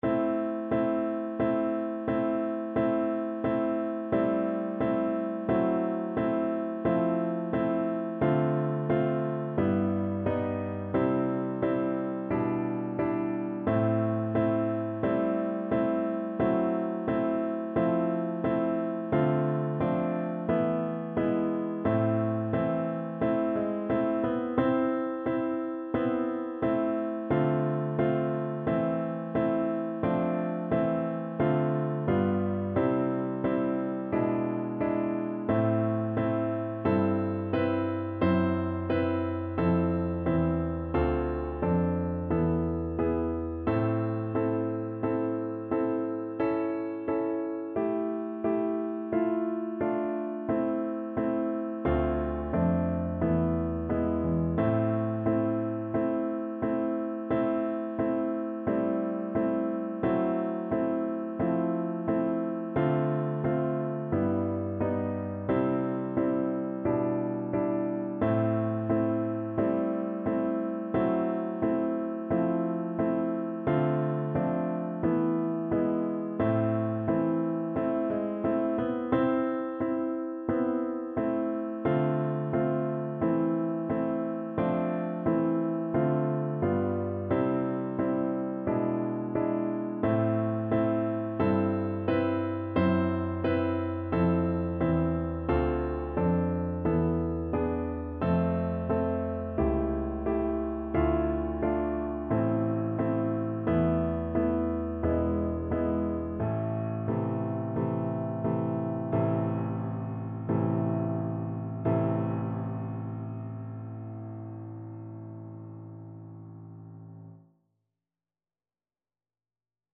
Play (or use space bar on your keyboard) Pause Music Playalong - Piano Accompaniment Playalong Band Accompaniment not yet available reset tempo print settings full screen
Traditional Music of unknown author.
A minor (Sounding Pitch) (View more A minor Music for Flute )
Andante =c.88